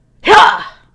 Audio / SE / Cries / STARYU.mp3